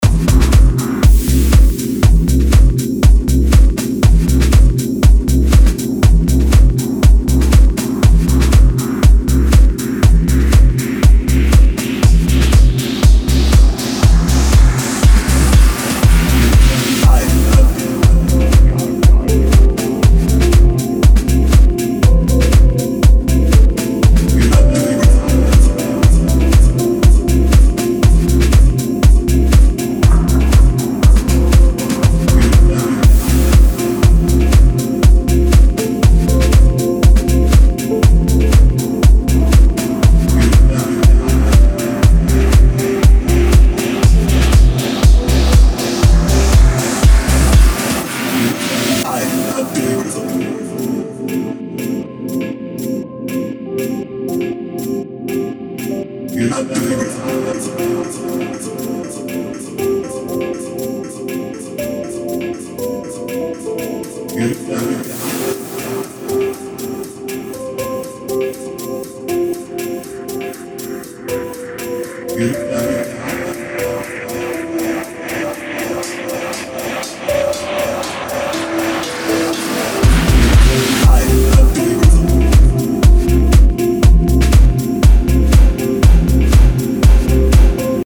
(House) немогу разобраться с бочкой
сделал то-ли дип толи амбиент.